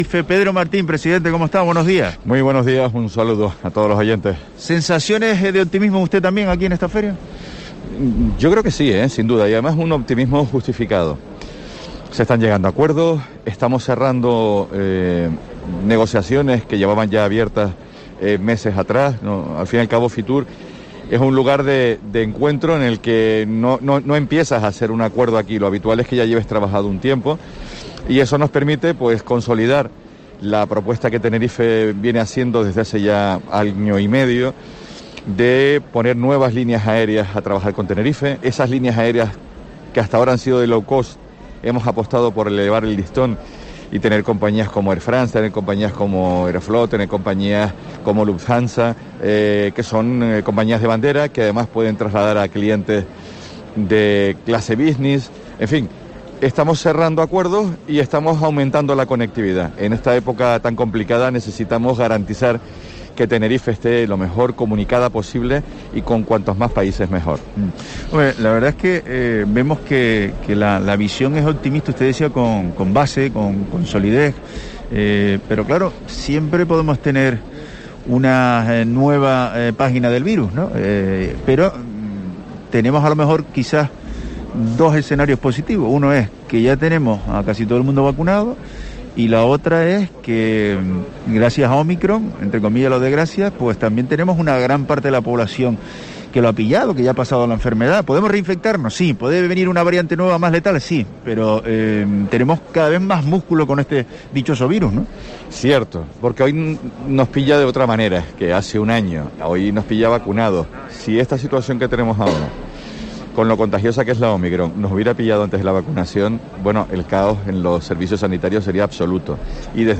Pedro Martín, presidente del Cabildo de Tenerife